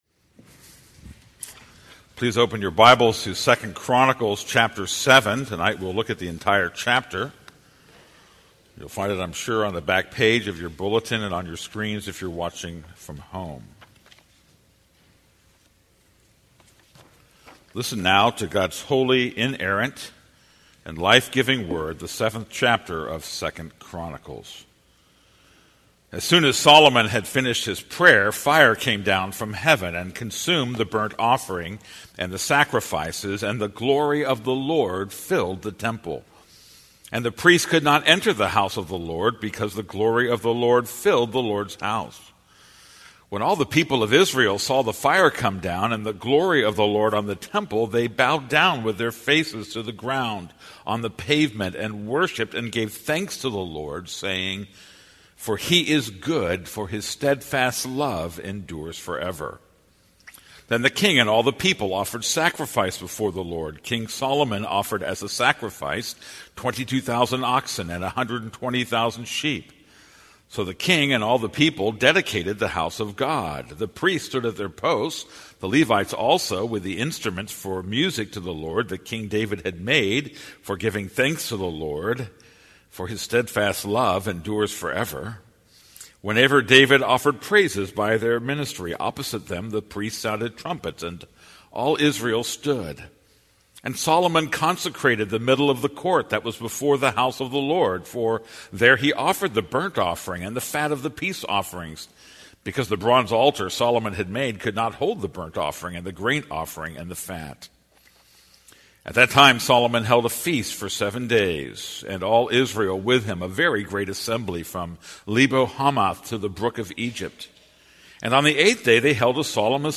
This is a sermon on 2 Chronicles 7:1-22.